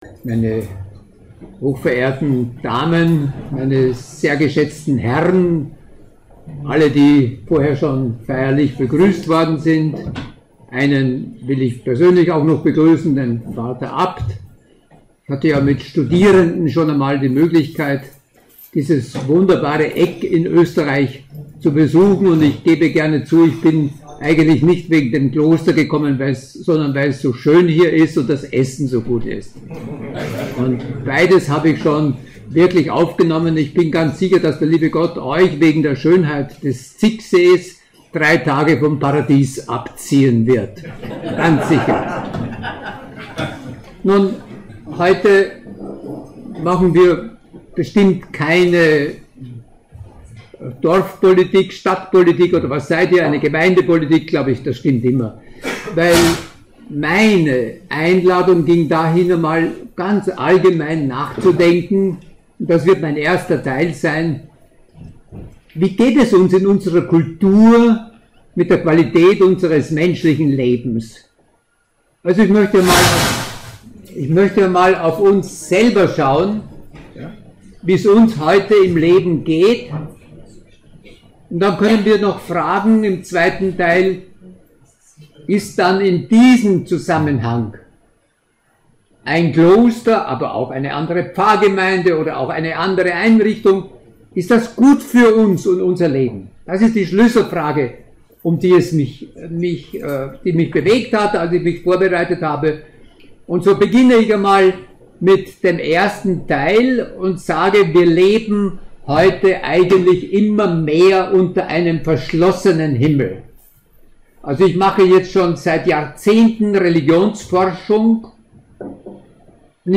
Audio-Mitschnitt des Vortrages vom 7. September 2018: